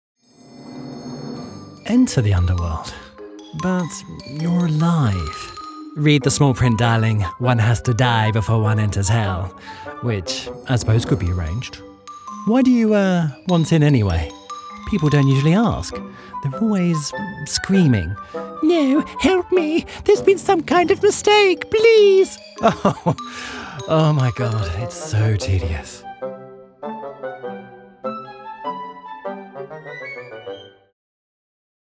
Gender: Male
Description of voice: I have a neutral British accent. My voice has a fresh, clear, measured and self-assured tone. Friendly, but authoritative if needed!
Home Recording Studio
Microphones: Neumann TLM 102